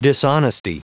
Prononciation du mot dishonesty en anglais (fichier audio)